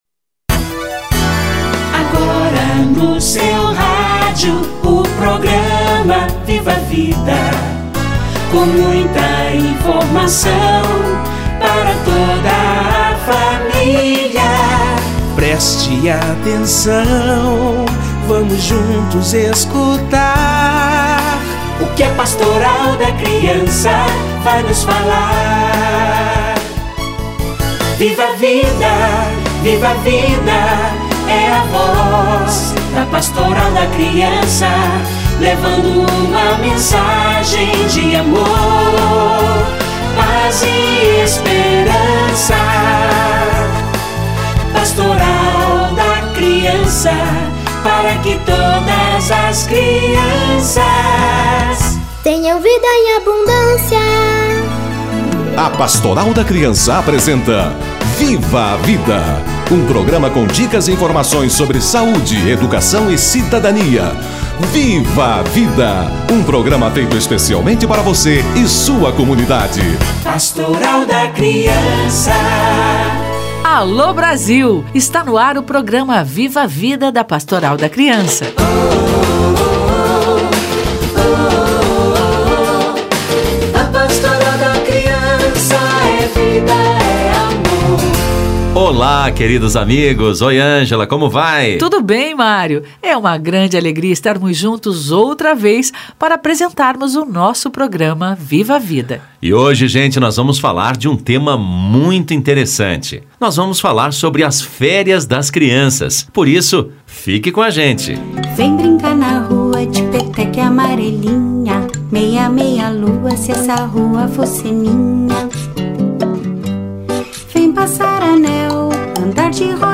Criatividade nas férias - Entrevista